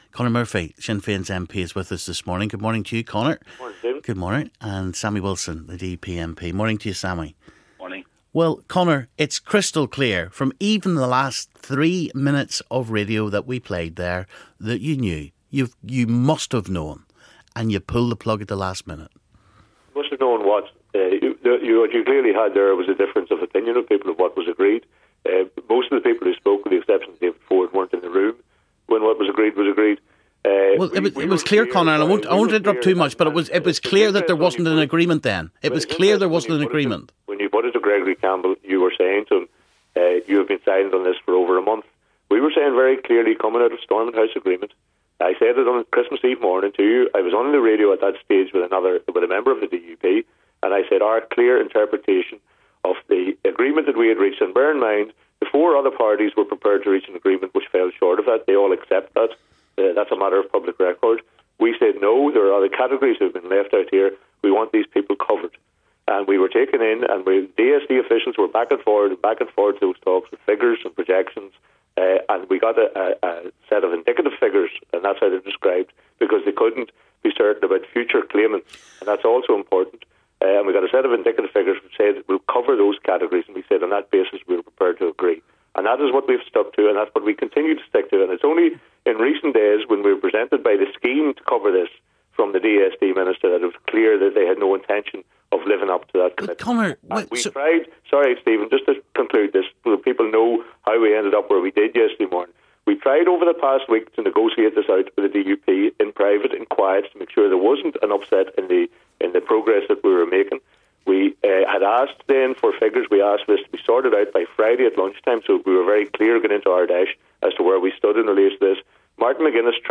Conor Murphy and Sammy Wilson debate Stormont welfare breakdown. [Part 1]